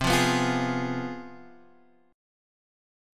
CM7sus4#5 chord